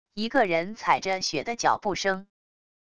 一个人踩着雪的脚步声wav音频